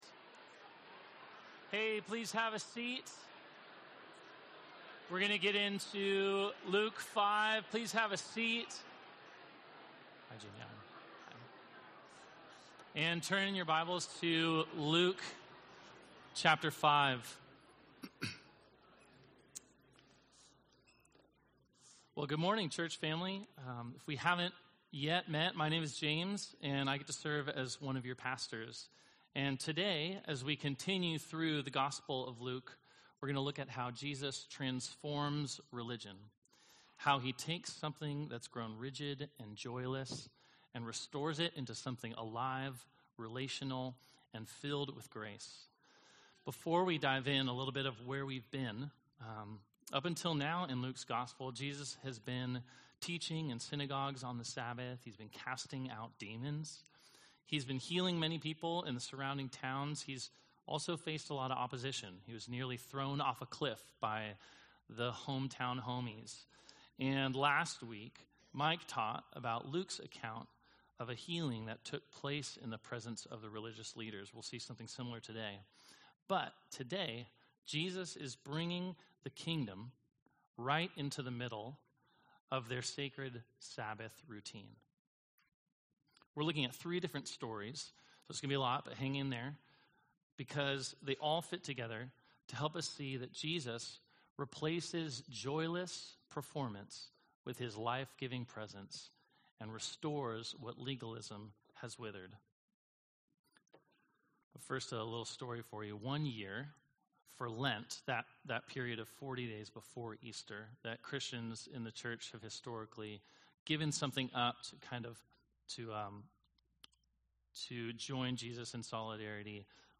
Gospel of Luke Passage: Luke 5:33–6:11 Service Type: Sunday « The Healing of a Cast-out